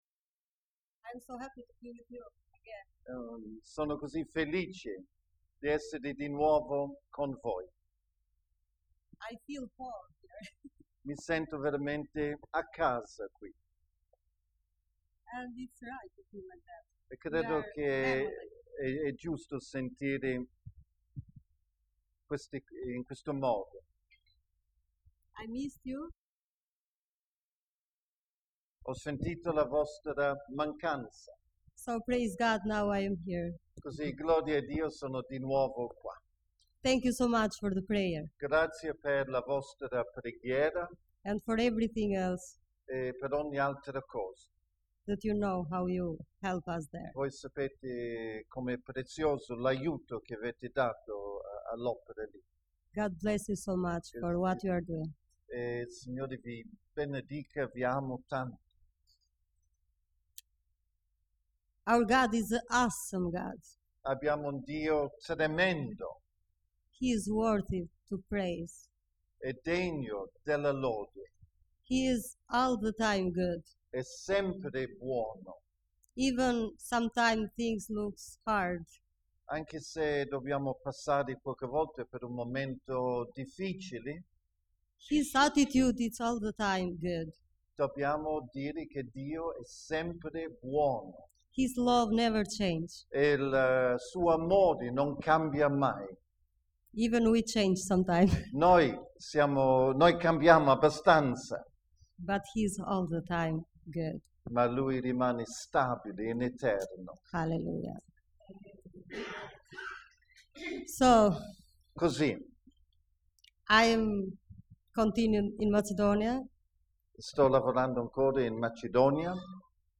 Testimonianza 16 giugno 2013 - Sorgi e risplendi!